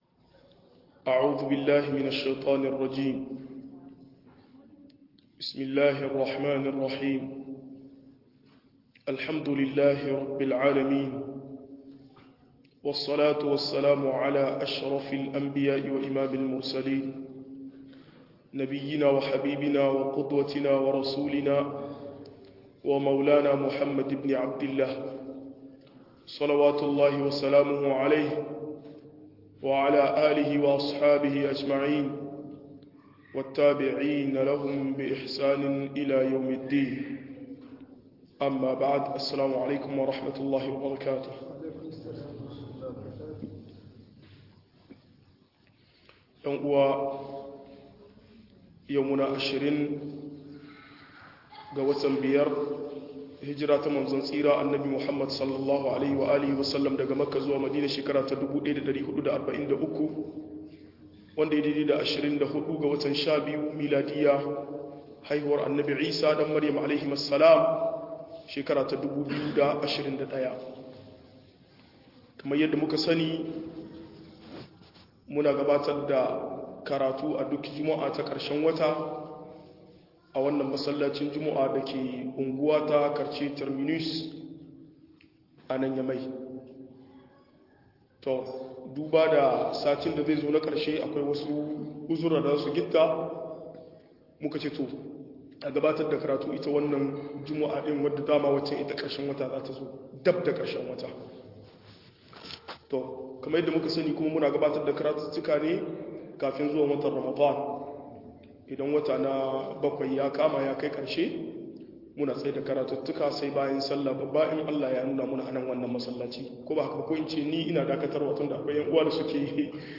Yadda girman kai ke bata tarbiyya - MUHADARA